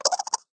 alien.ogg